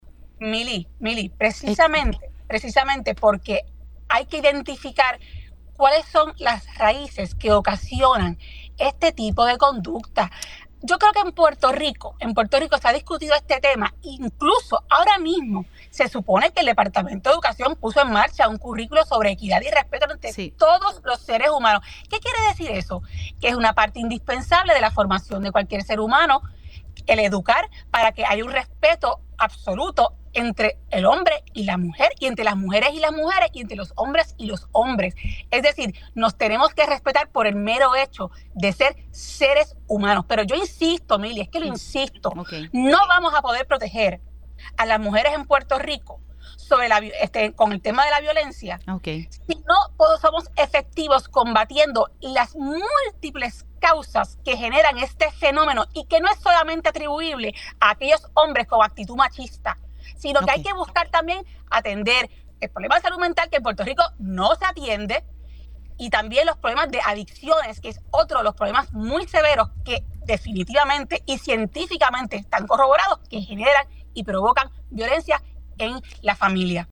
A través de una video llamada en Pega’os en la Mañana, hoy, viernes,  Rodríguez Veve quien está involucrada en un caso bajo investigación por parte de la Oficina de la Procuradora de la Mujer, comunicó que es importante “defender la vida humana y respetarla en todo momento”.